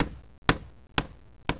Effet sonore - wav Cliquez dans le pot de casserole stéréo mp3
Cliquez dans le pot de casserole stéréo
Cliquez comme une balle de tennis ou un ping-pong rebondissant en stéréo.